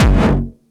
VEC3 Bassdrums Dirty 18.wav